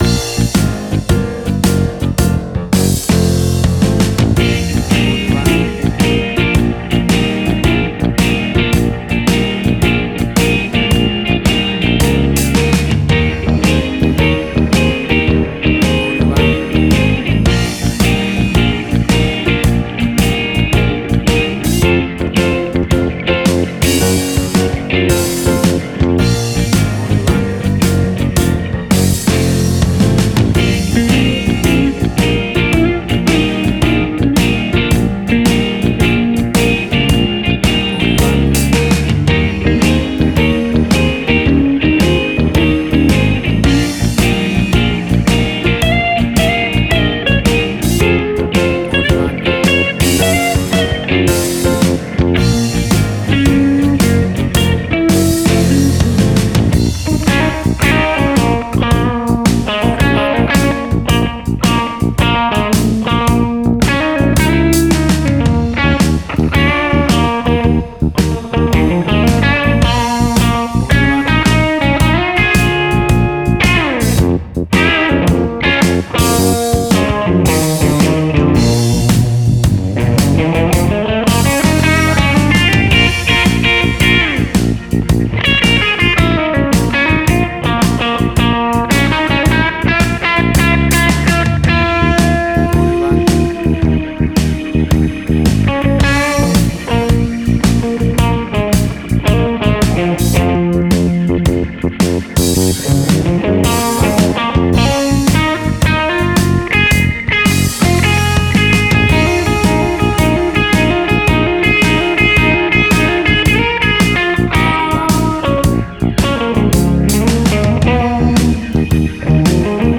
A Bouncy piece of swinging classic Blues Music.
Tempo (BPM): 110